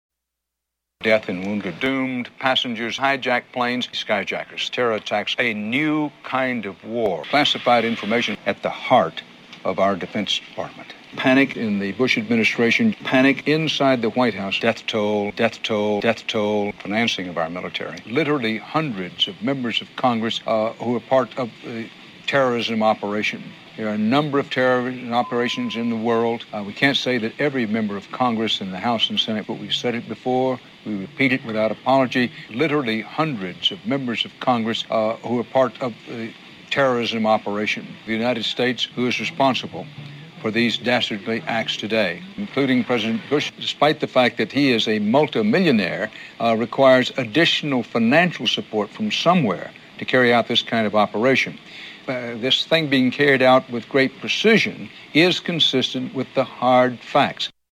Dan Rather Collage
You may also hear the occasional cuss word in some translations: you have been warned.